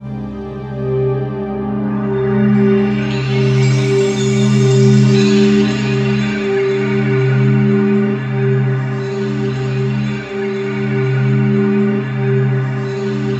SWEEP05   -L.wav